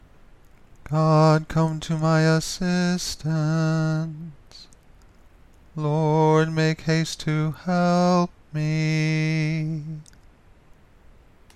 Caveat: this is not Gregorian Chant.